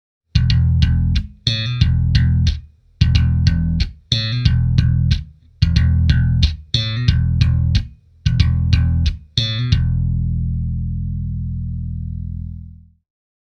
Tässä muutama esimerkki Eden EGRW1264 -setin soundeista (soitettu japanilaisella Jazz-bassolla):
Slap
slap.mp3